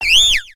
Grito de Foongus.ogg
Grito_de_Foongus.ogg